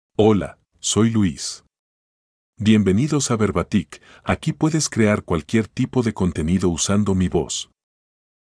Luis — Male Spanish (Ecuador) AI Voice | TTS, Voice Cloning & Video | Verbatik AI
MaleSpanish (Ecuador)
Luis is a male AI voice for Spanish (Ecuador).
Voice sample
Male